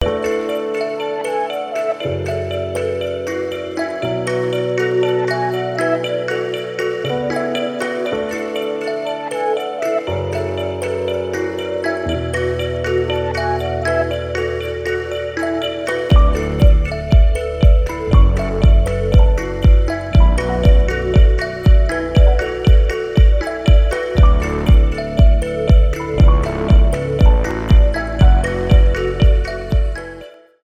спокойные , красивые
без слов
electronic , deep progressive